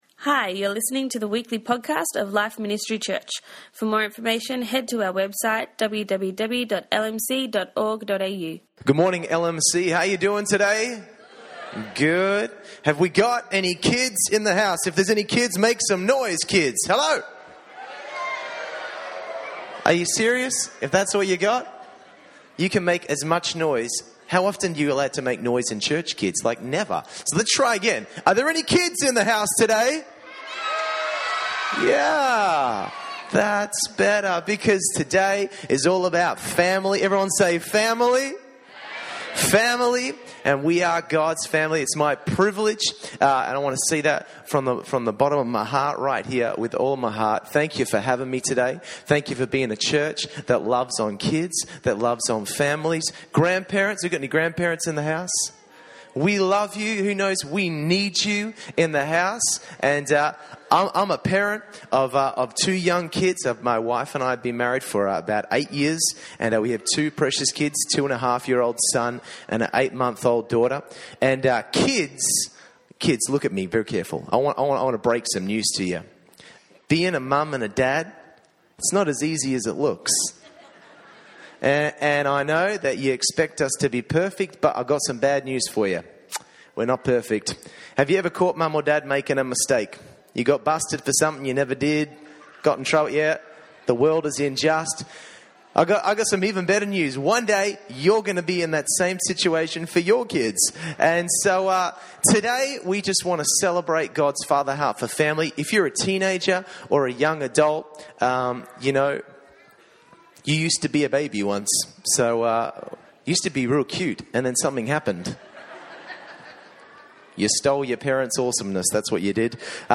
Family Service 2015